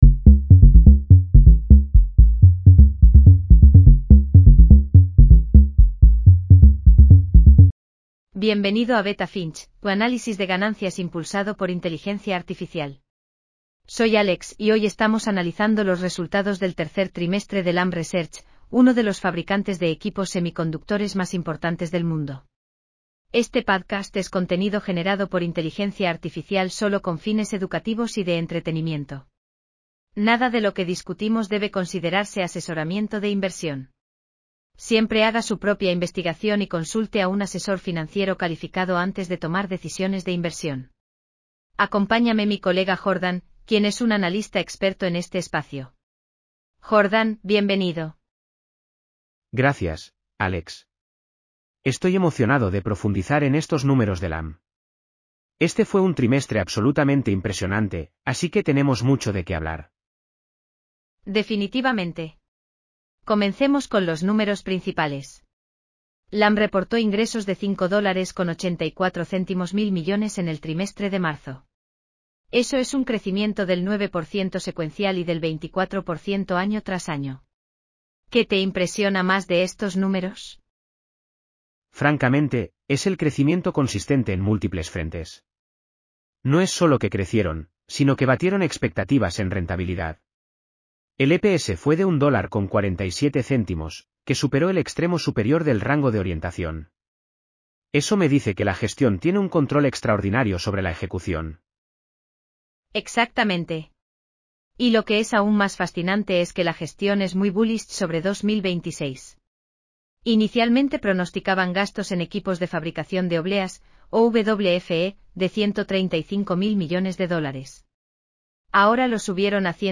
Bienvenido a Beta Finch, tu análisis de ganancias impulsado por inteligencia artificial.